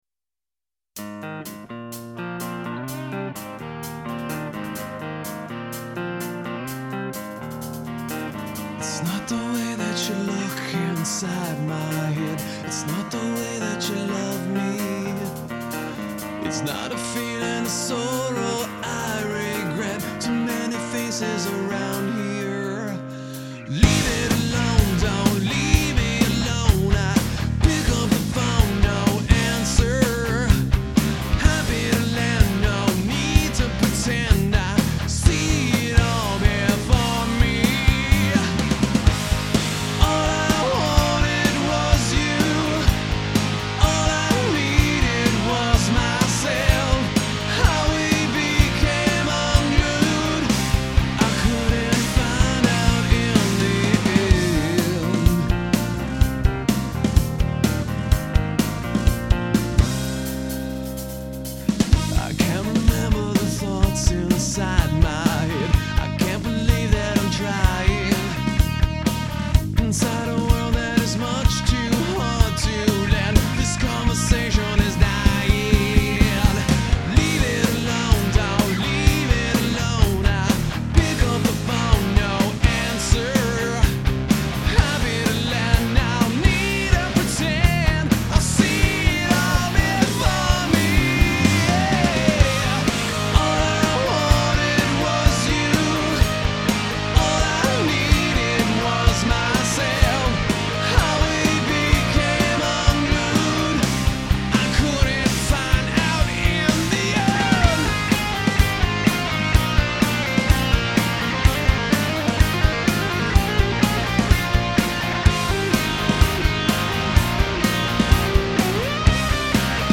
radio-friendly alt-rock sound
crunching guitar riffs and a super solid drum atta